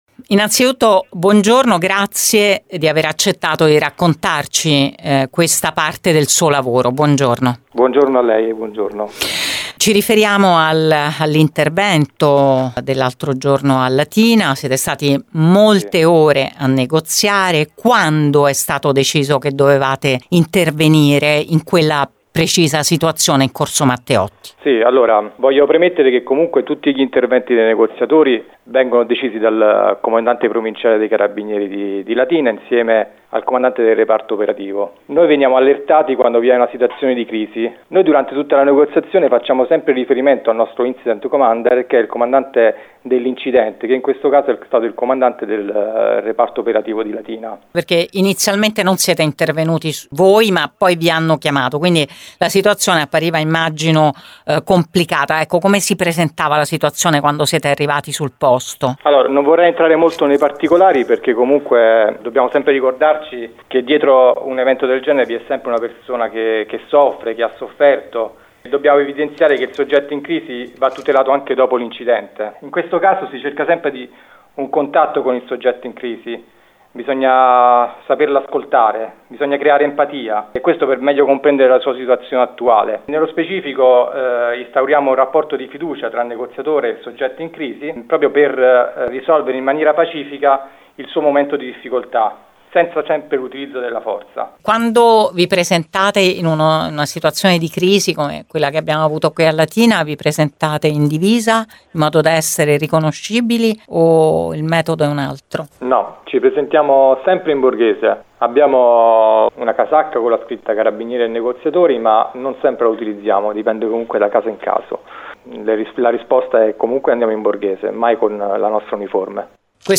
QUI L’INTERVISTA INTEGRALE IN FORMATO AUDIO